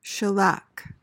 PRONUNCIATION: (shuh-LAK) MEANING: noun: A resin secreted by the lac insect and purified for use in varnishes, paints, inks, sealing waxes, phonograph records, etc. A phonograph record, especially a 78 rpm.